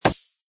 woodplastic.mp3